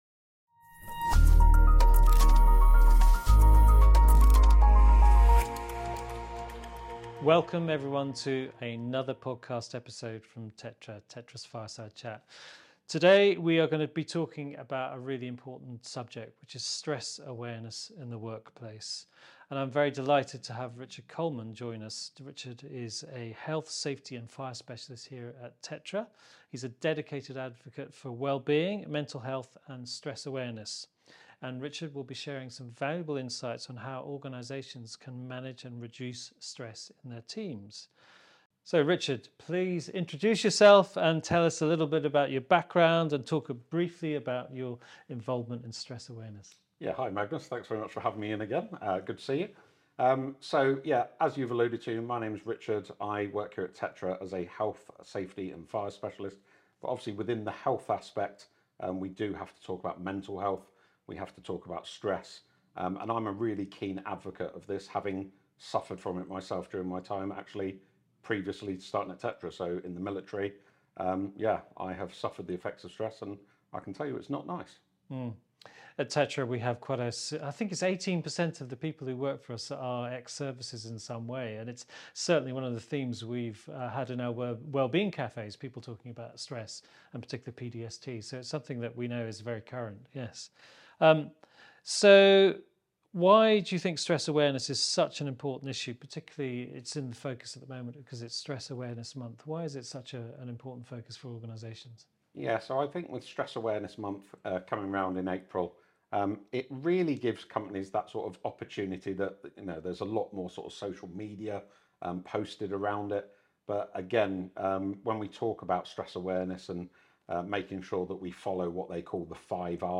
The conversation looks back at a year where the Building Safety regime shifted decisively from preparation to delivery.